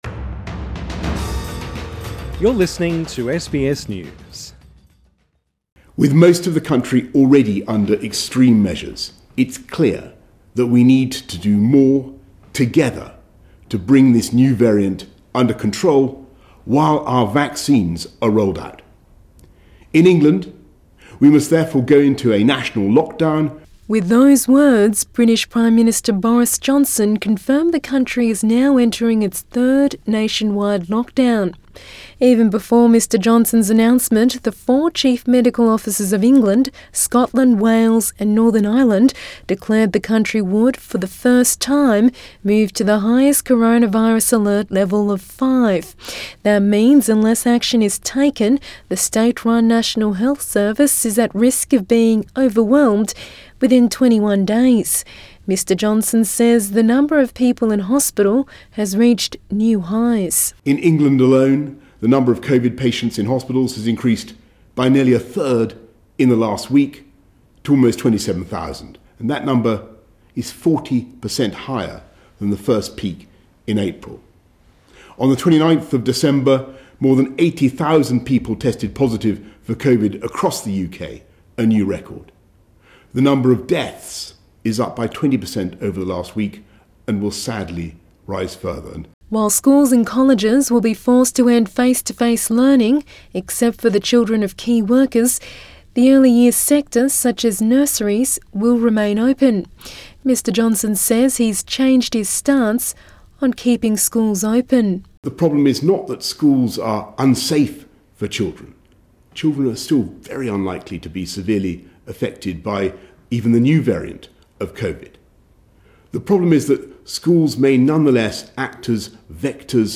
Boris Johnson outlines new lockdown for England in a televised address to the nation.